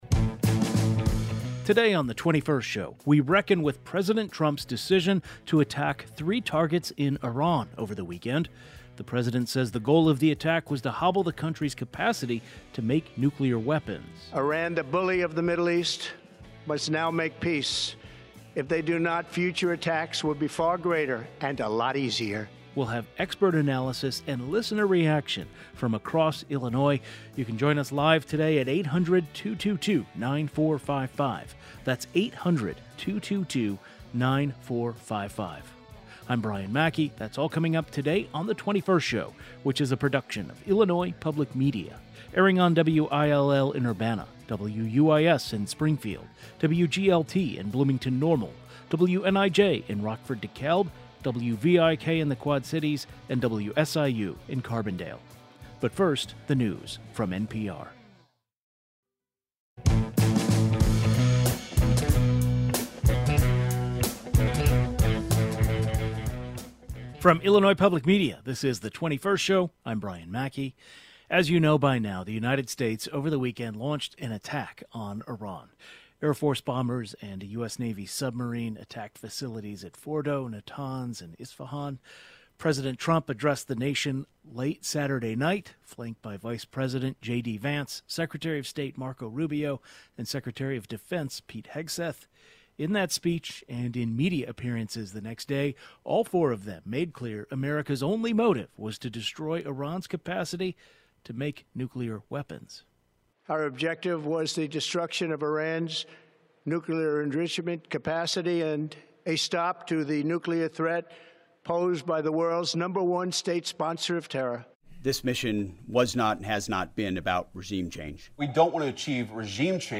A panel of Middle East and geopolitics experts share their reactions to the U.S.'s move and an analysis of the repercussions.